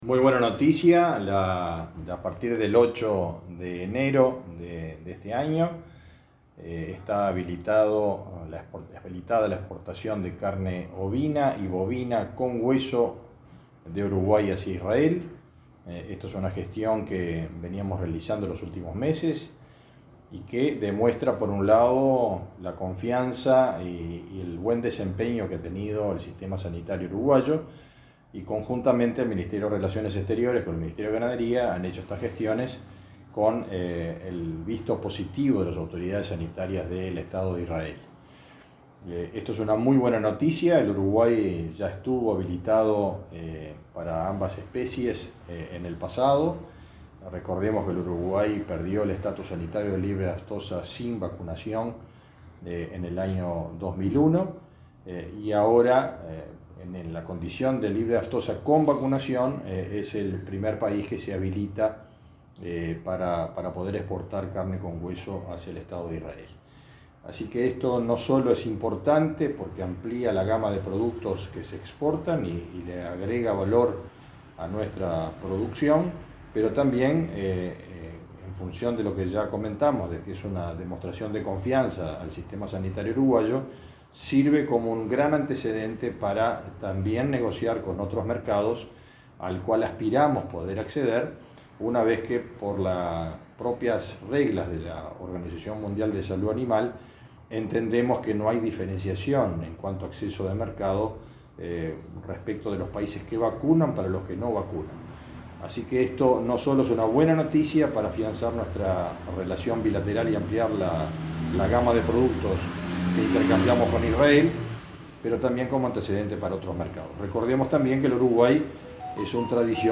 Entrevista al ministro de Ganadería, Fernando Mattos